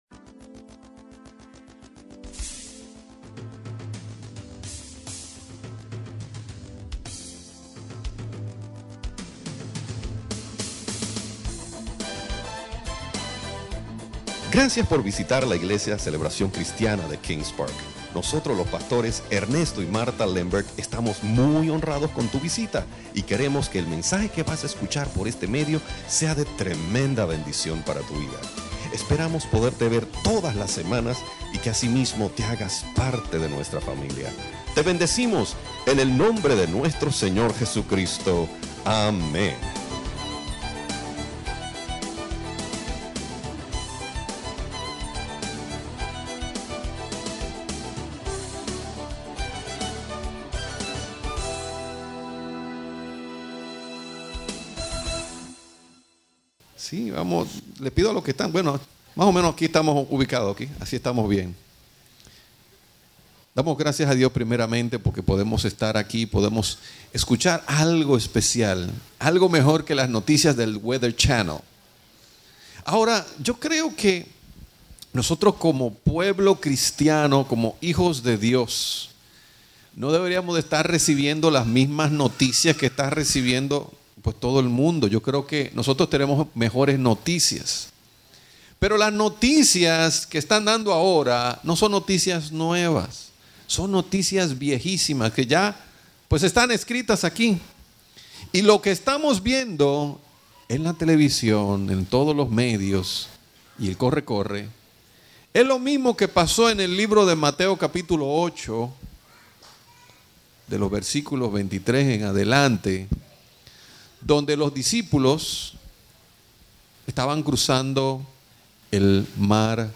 Estudio Bíblico Predicadores